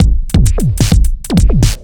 OTG_Kit10_Wonk_130b.wav